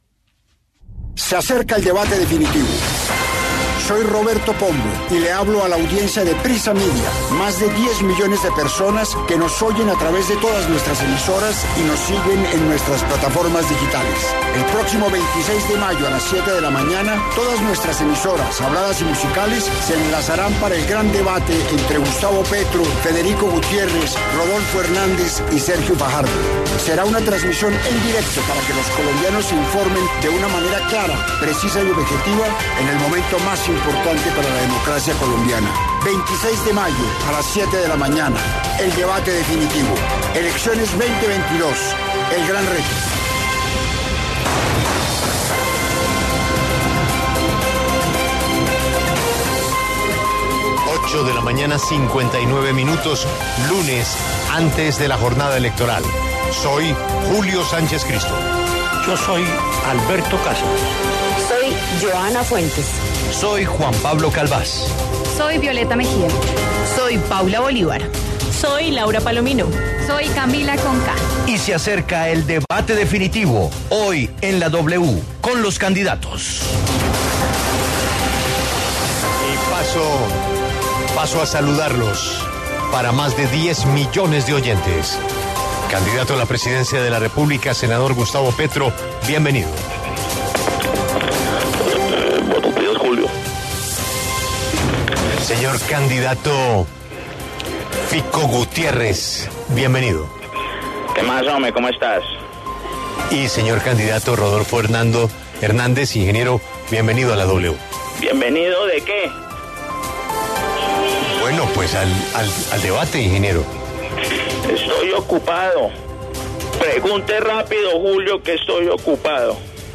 En los micrófonos de La W